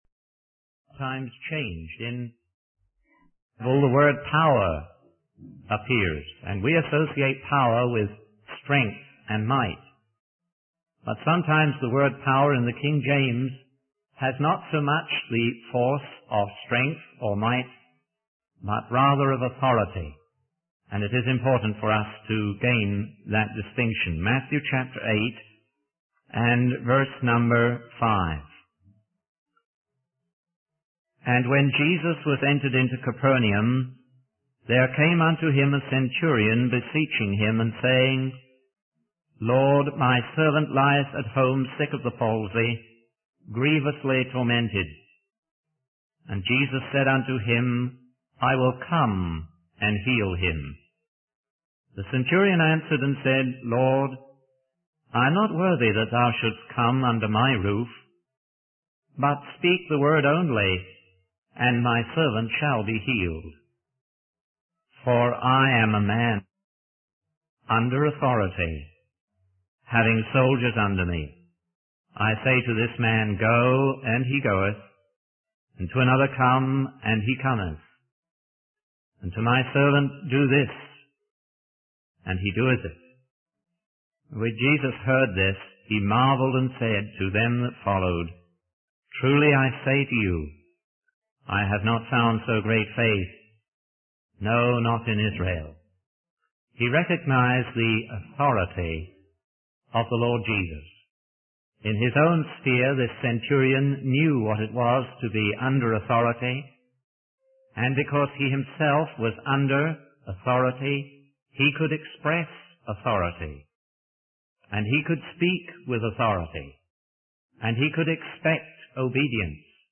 In this sermon, the speaker discusses the importance of authority and obedience in the Bible. He starts by referencing Hebrews 13:7, which encourages believers to obey and submit to their leaders.